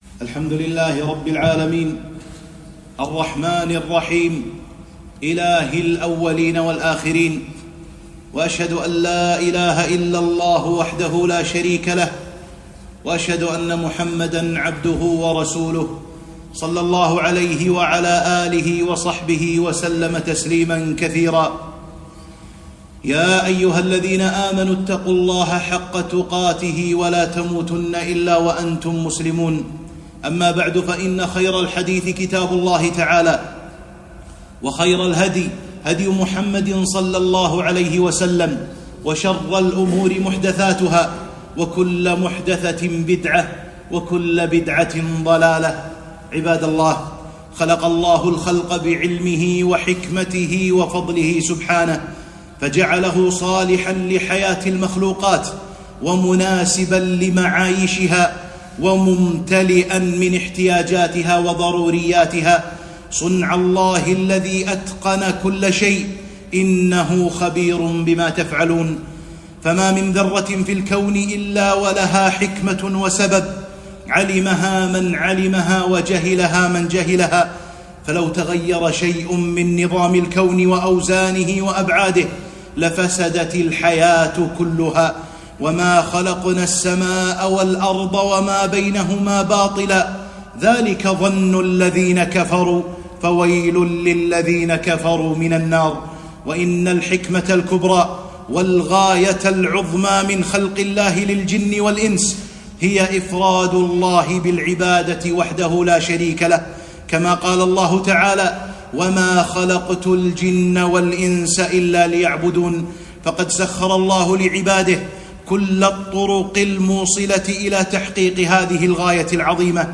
خطبة - اشتداد حر الصيف، وانقطاع الكهرباء